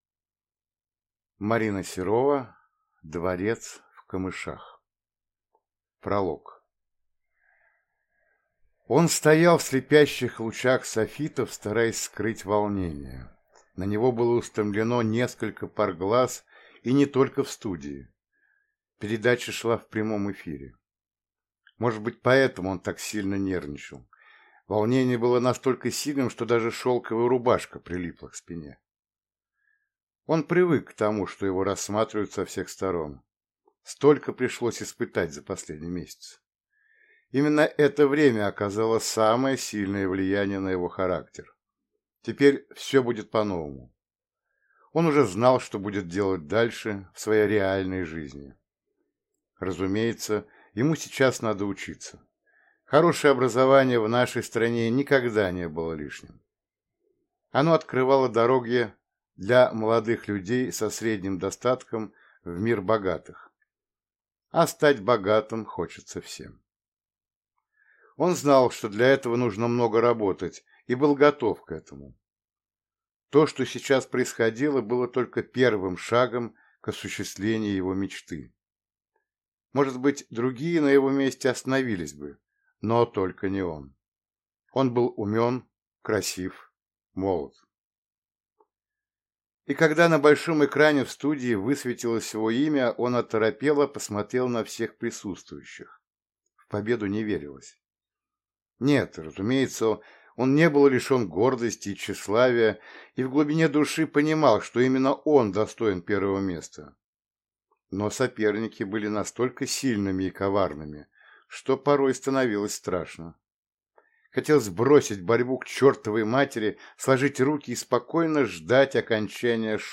Аудиокнига Дворец в камышах | Библиотека аудиокниг